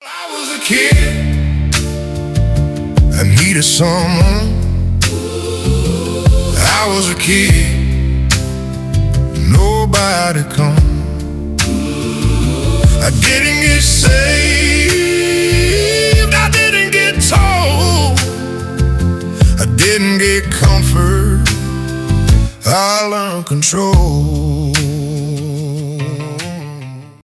кантри , блюз , country rock